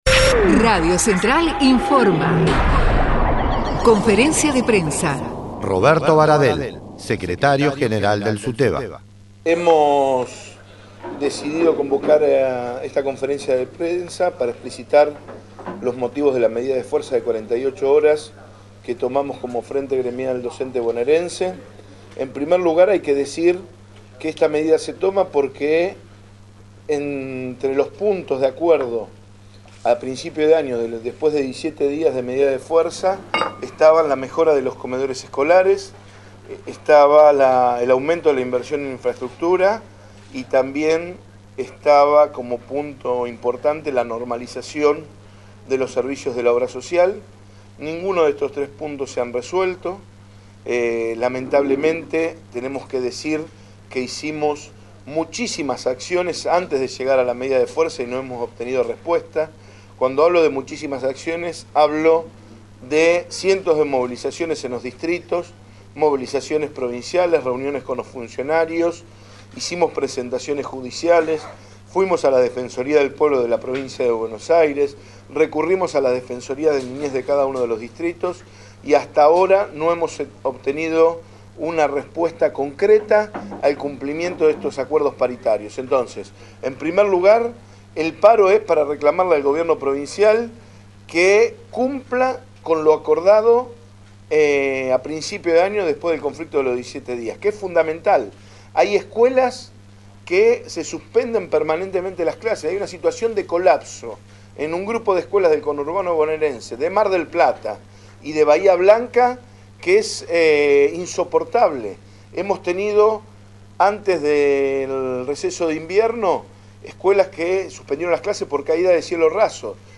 ROBERTO BARADEL - SUTEBA: conferencia de prensa – Central de Trabajadores y Trabajadoras de la Argentina
Conferencia de prensa del secretario General del Sindicato Unificado de Trabajadores de la Educación de la provincia de Buenos Aires